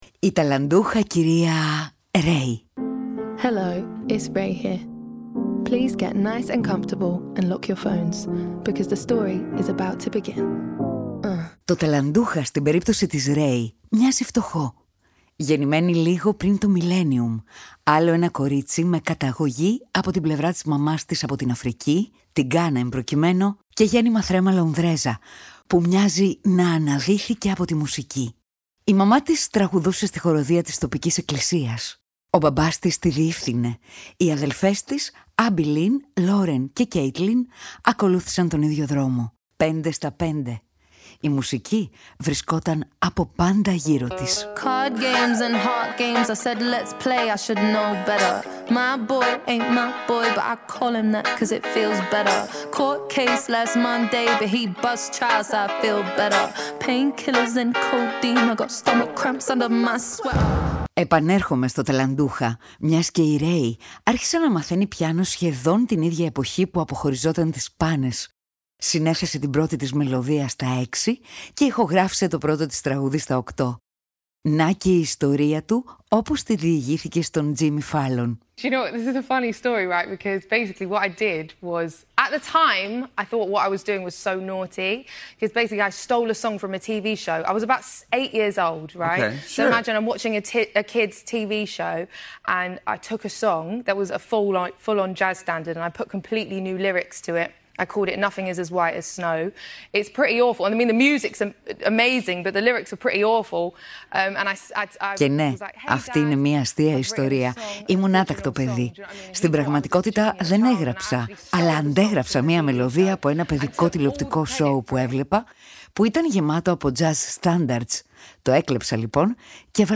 Ιστορίες, αφηγήσεις και σχόλια για να συστηθούμε καλύτερα.